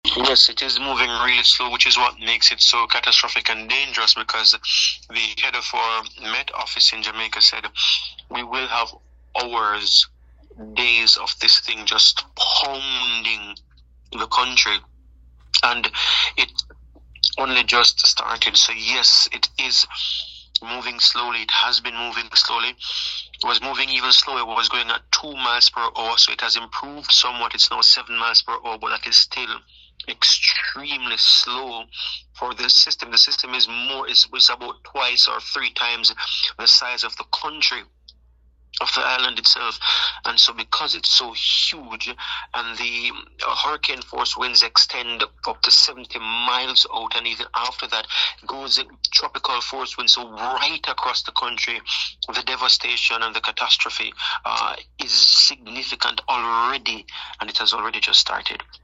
Esto es solo el principio“, aseguró mediante llamada telefónica.